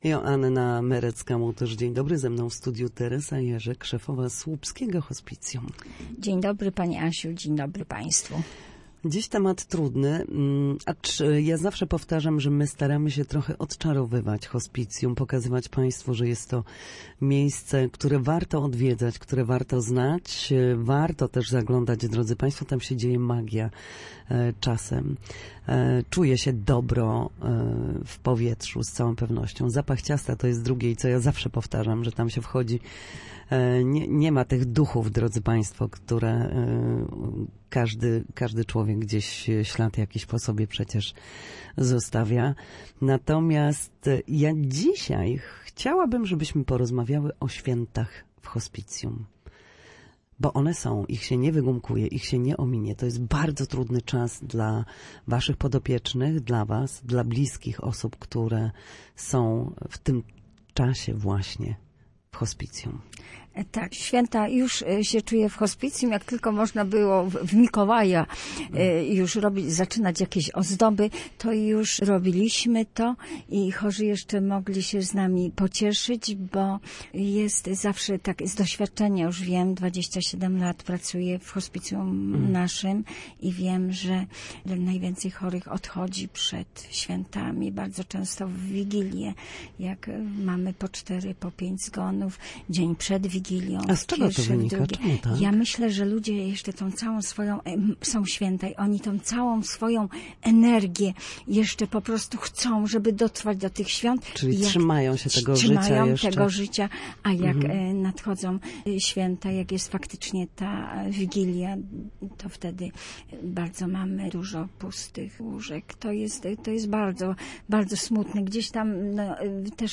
Święta w hospicjum to czas bliskości i obecności. Gościem Studia Słupsk